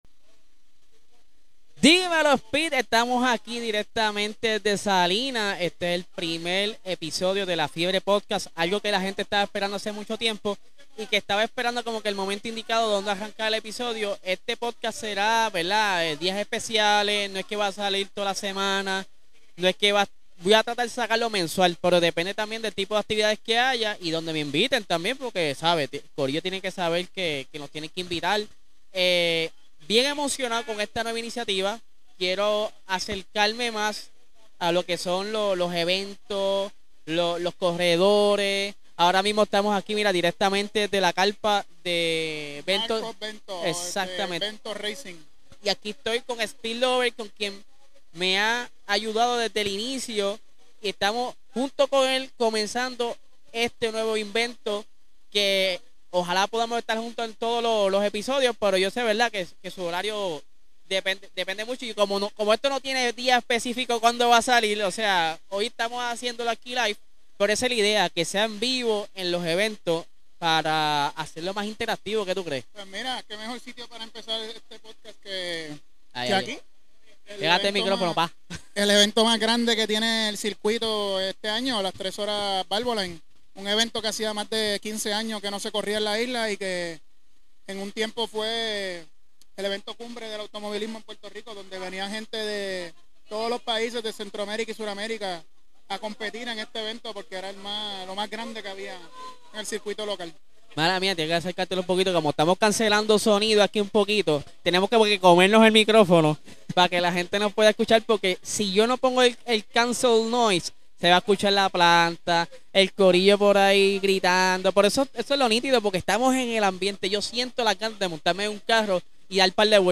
ARRANCAMOS CON NUEVO PODCAST DIRECTAMENTE DESDE SALINAS!!!